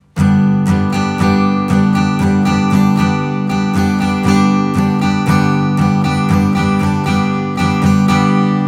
5. Schlagmuster für Gitarre